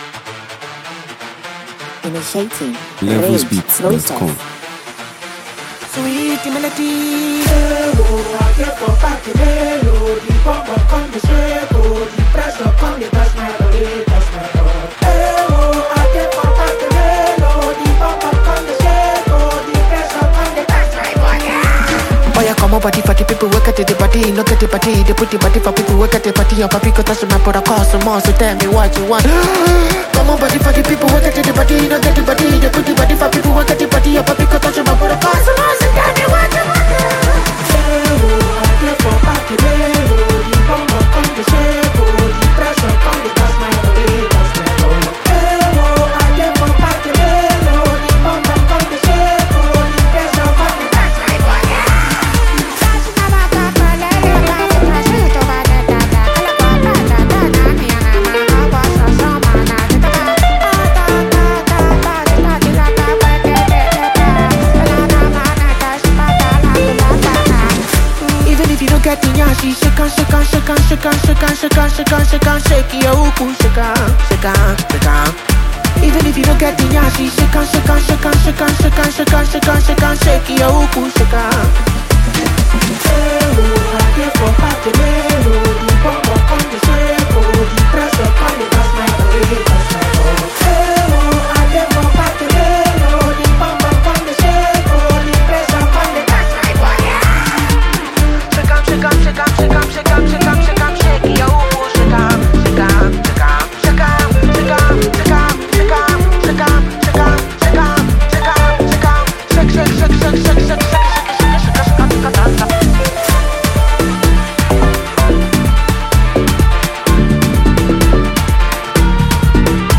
Vibrant New Anthem
With its upbeat tempo, irresistible groove